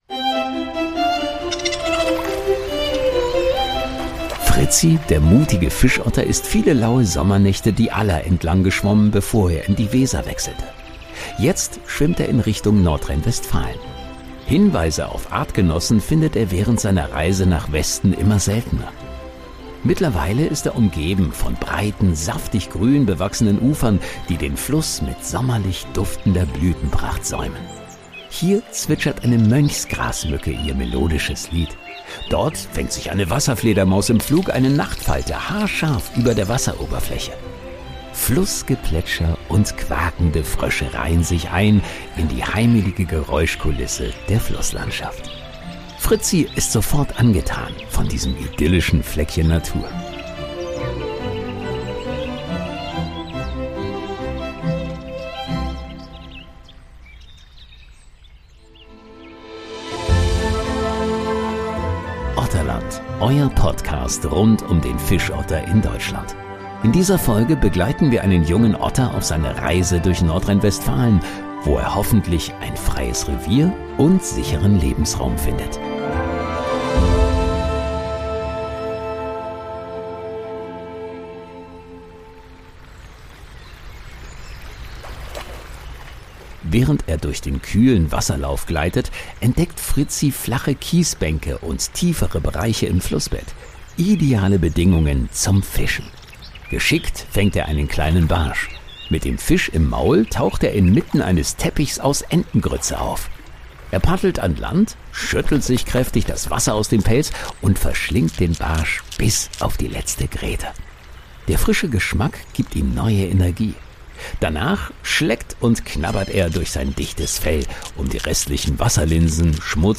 „Otterland“ ist ein Storytelling-Podcast, der eine fiktive Geschichte über eine Otterfamilie quer durch Deutschland erzählt, basierend auf realen Erfahrungen und Fakten zu Fischottern. Ein Hörabenteuer über Mut, Hoffnung, Verlust und die Suche nach einem Zuhause.